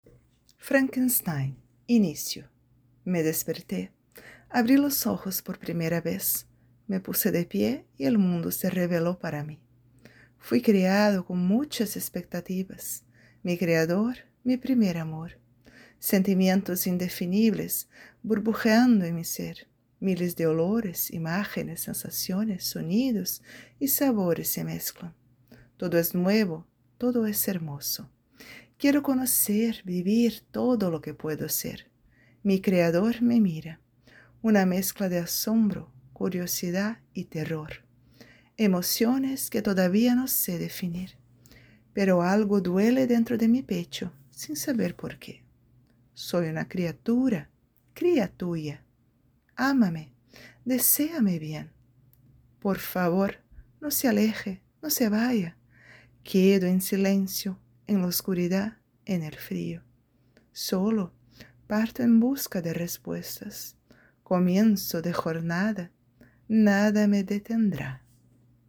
Poesías
Tema mu sical Happy Halloween - Feliz Día de las Brujas